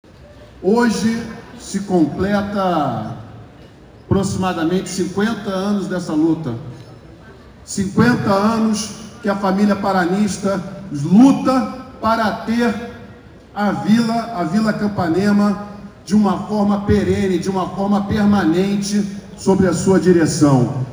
Na noite desta quarta-feira (26), no Espaço Torres, o Ministro fez um discurso parabenizando o Paraná Clube pela conquista, mas a falta de conhecimento em relação à instituição clamou a atenção dos cerca de 250 convidados na cerimônia.